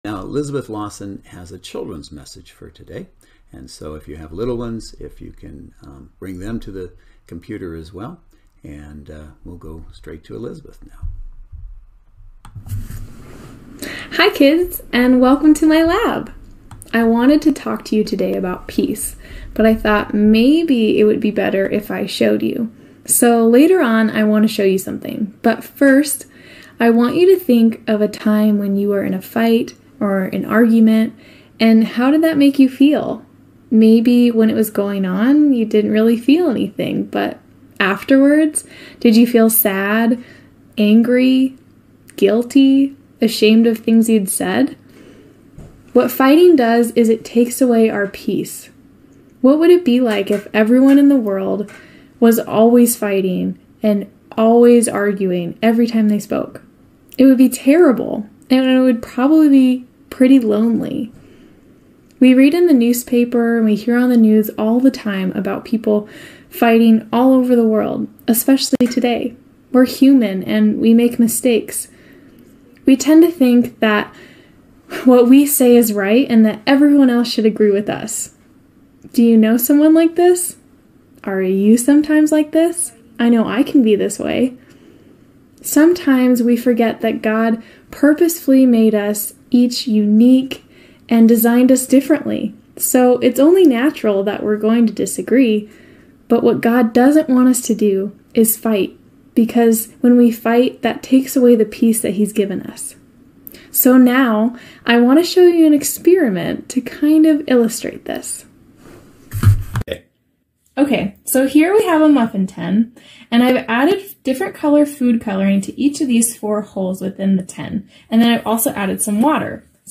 Faith Not Fear Service Type: Saturday Worship Service Speaker